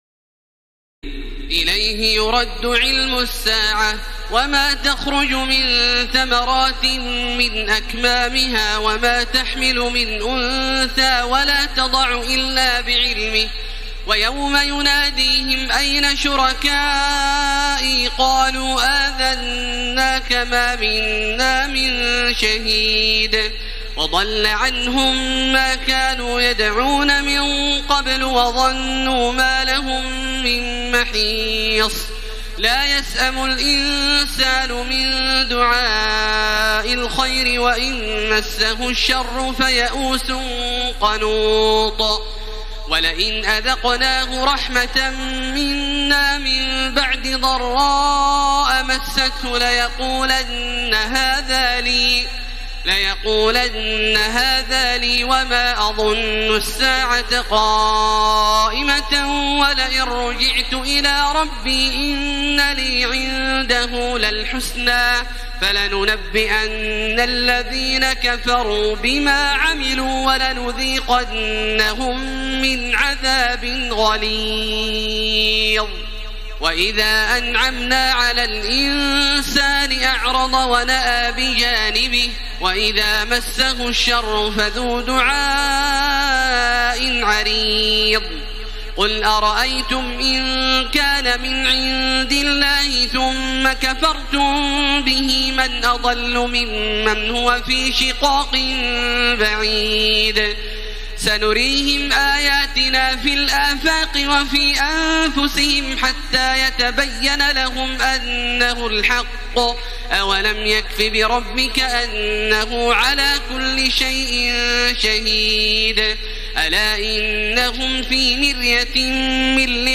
تراويح ليلة 24 رمضان 1434هـ من سور فصلت (47-54) و الشورى و الزخرف (1-25) Taraweeh 24 st night Ramadan 1434H from Surah Fussilat and Ash-Shura and Az-Zukhruf > تراويح الحرم المكي عام 1434 🕋 > التراويح - تلاوات الحرمين